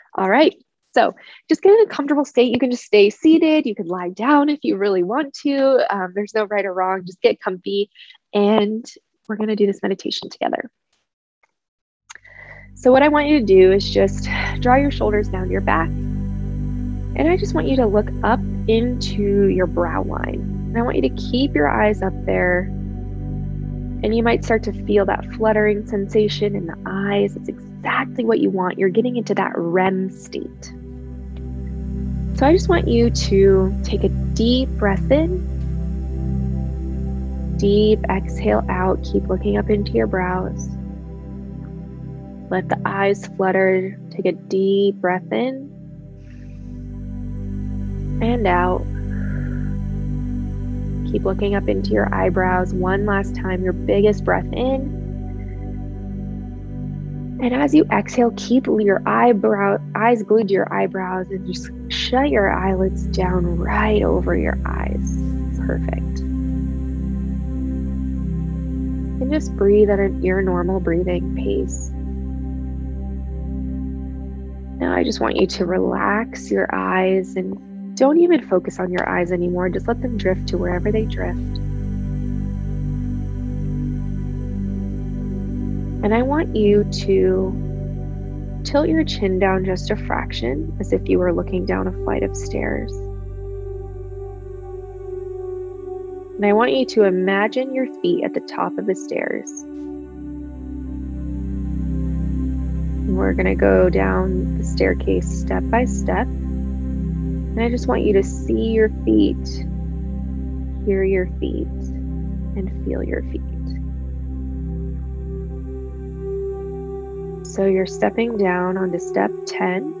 This guided meditation is created to allow your subconscious mind to come forward in a theta wave state (this is why I have you activate the fluttering of your eyes in the beginning to go into REM and allow your mind and nervous system to calm much quicker and deeper).